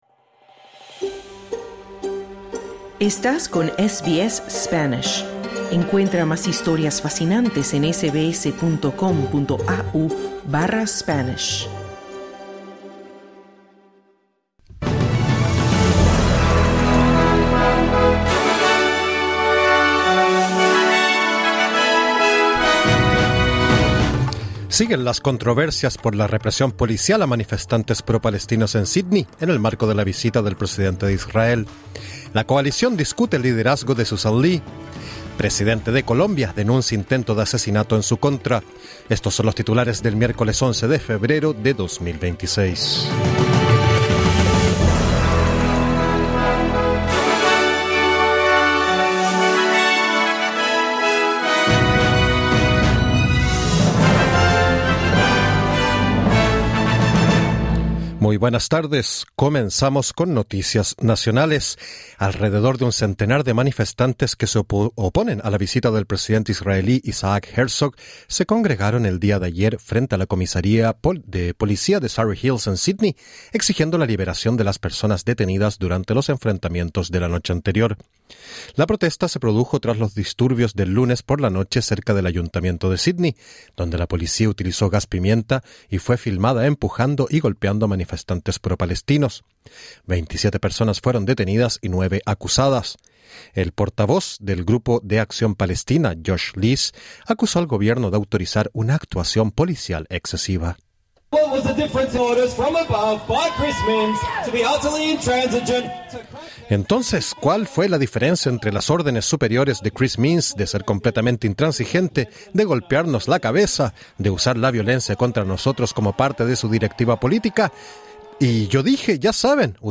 Boletín 11/02/26: Diversos líderes y representantes sociales han defendido o criticado la acción policial contra los manifestantes propalestinos en protesta por visita de presidente de Israel. El liderazgo de la coalición sigue en entredicho, y presidente de Colombia denuncia un nuevo intento de asesinato.